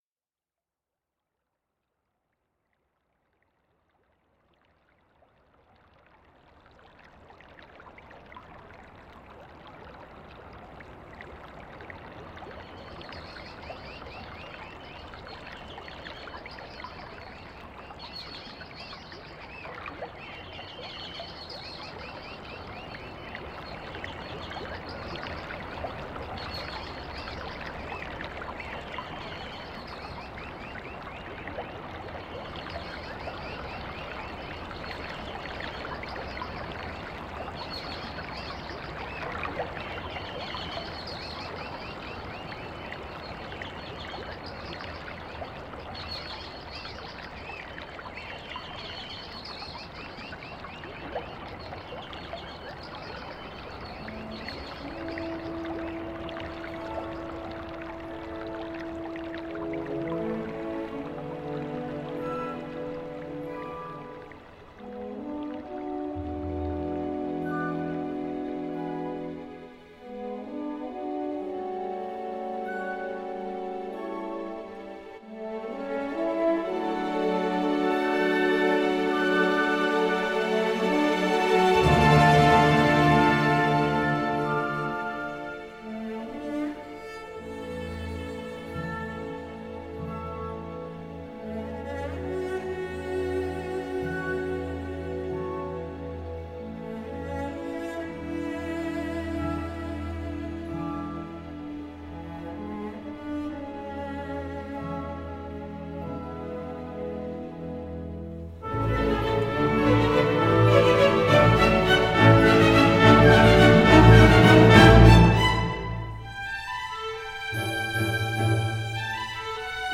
2周前 纯音乐 5